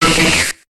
Cri d'Élekid dans Pokémon HOME.